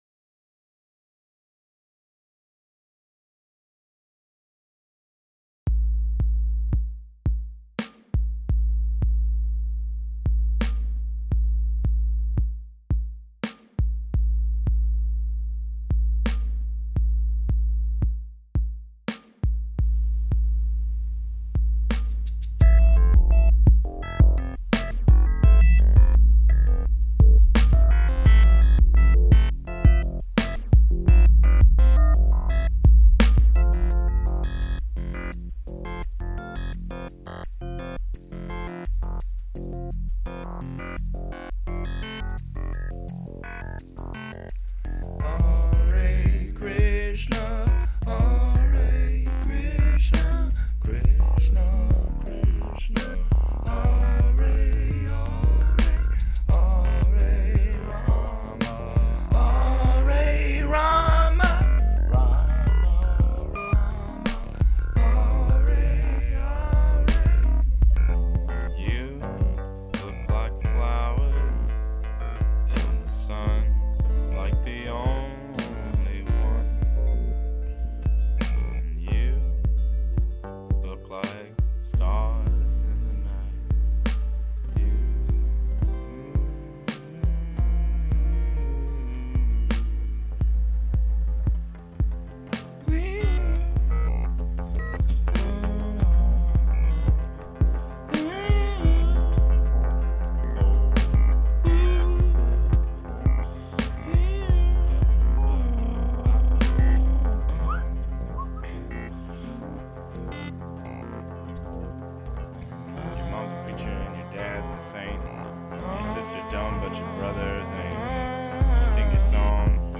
It’s called Golden Girl, and it has a hip-hop tinge.